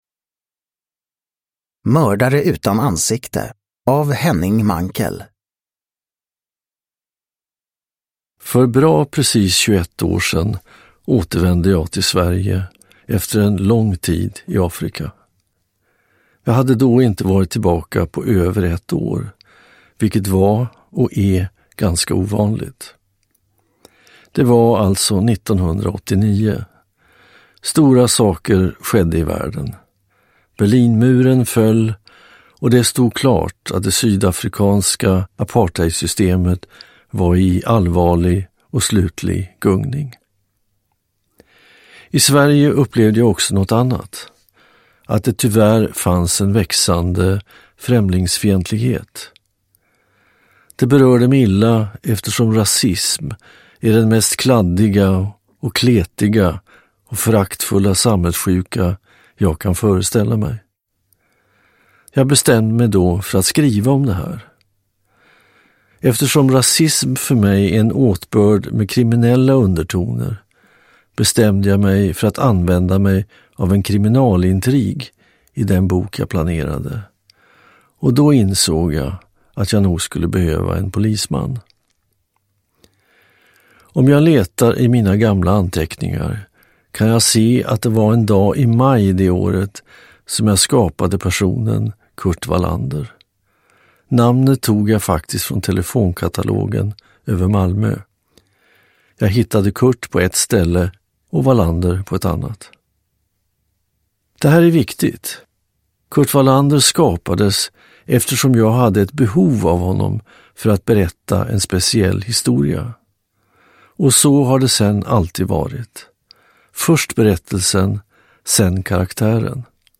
Mördare utan ansikte – Ljudbok
Här i Stefan Sauks originalinspelning.
Uppläsare: Stefan Sauk